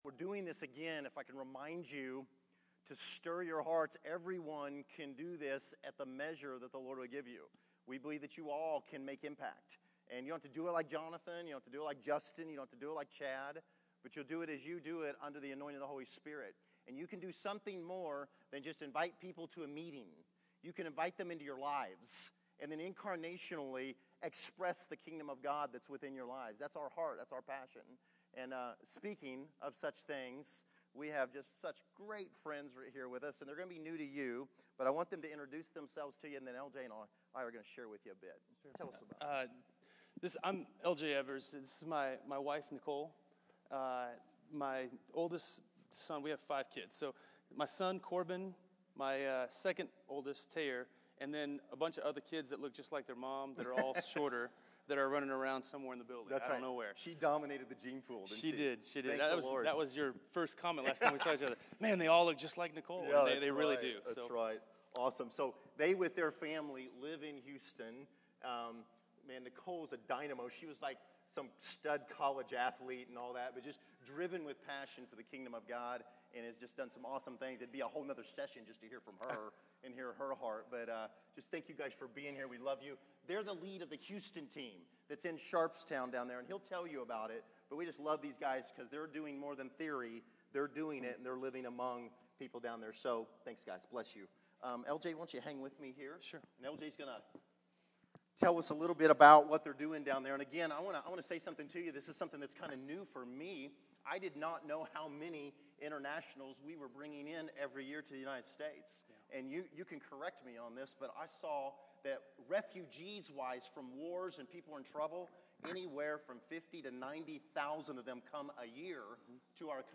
Category: Sermons